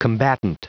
Prononciation du mot combatant en anglais (fichier audio)
Prononciation du mot : combatant